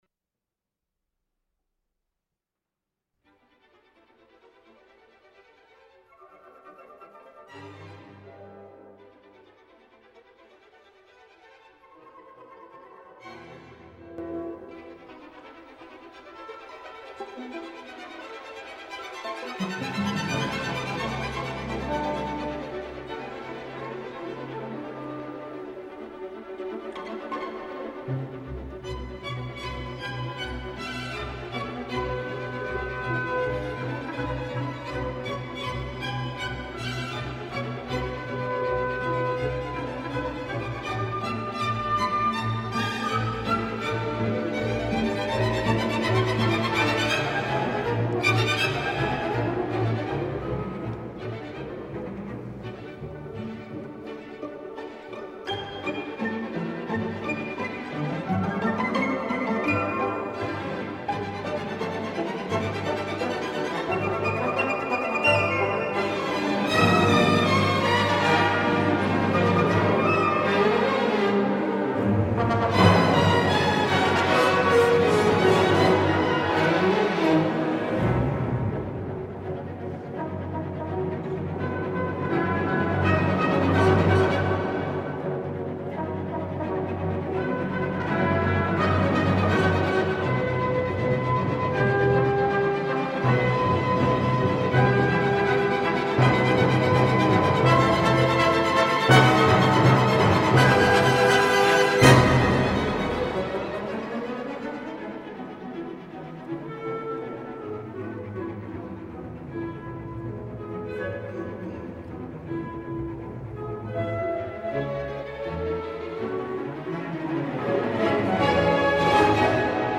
enérgico tercer movimiento
Allegro molto vivace